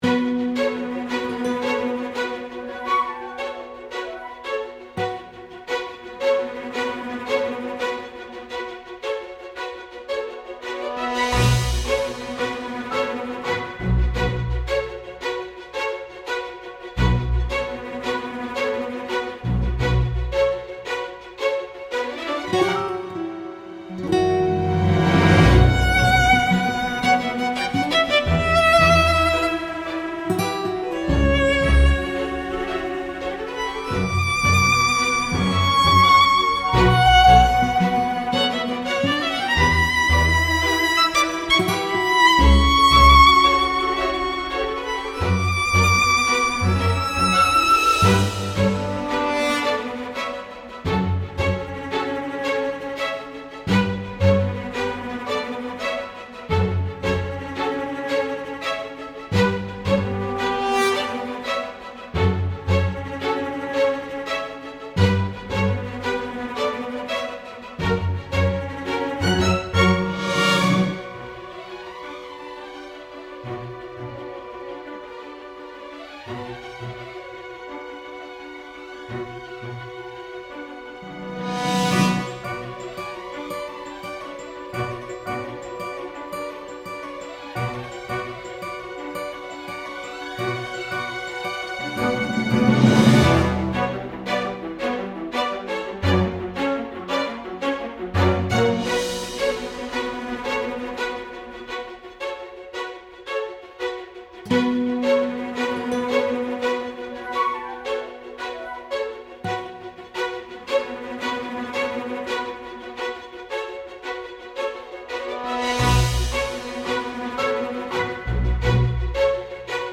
バイオリンが奏でる情緒的なメロディが悲劇的な運命を感じさせるフリーBGMです。